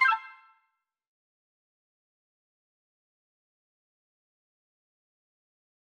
confirm_style_4_004.wav